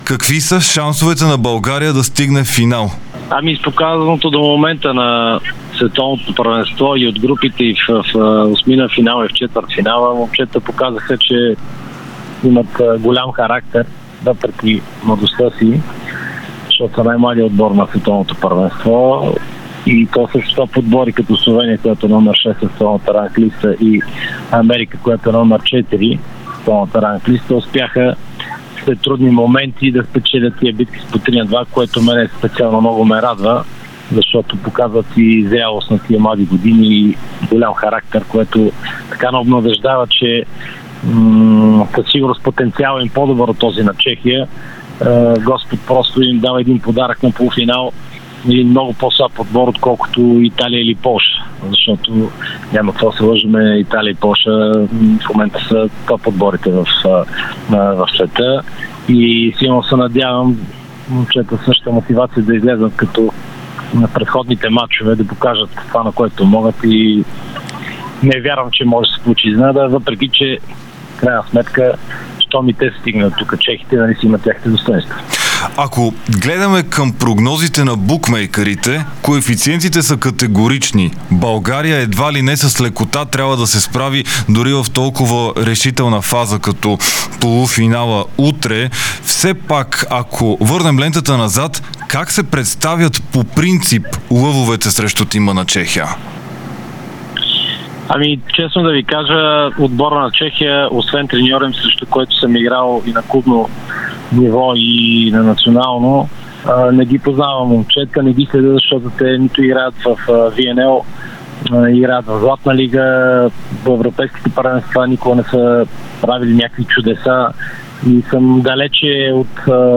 Един от най-успешните български волейболисти Теодор Салпаров сподели в Новините на Дарик в 18:00 часа очакванията си преди полуфинала срещу Чехия на Световното първенство във Филипините.